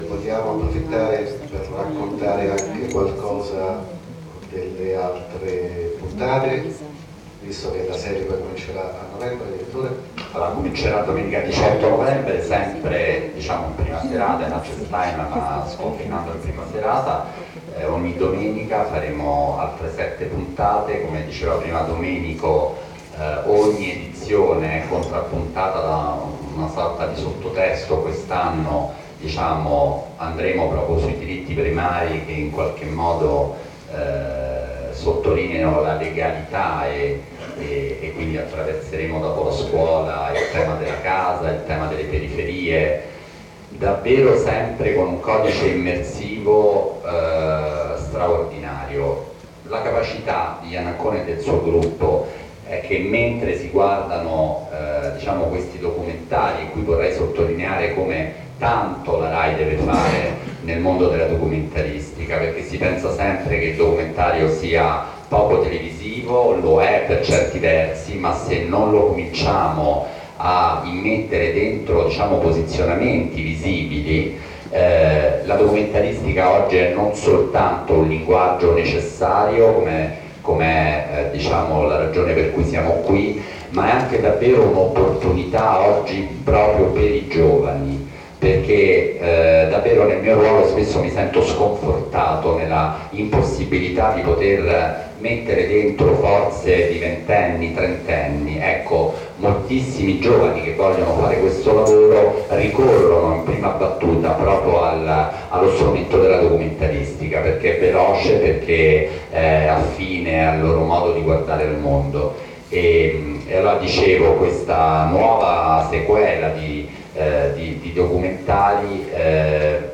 Lo speciale Come figli miei è stato presentato alla stampa lunedì 22 ottobre nella sede RAI di Viale Mazzini a Roma.
Infine il direttore Coletta ha ricordato che la settima nuova serie de I Dieci Comandamenti andrà in onda da domenica 16 novembre, per 6 puntate, la domenica alle 20.30 su RAI 3: